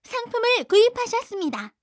ui_sysmsg_item_buy.wav